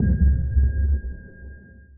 sonarTailSuitMediumShuttle3.ogg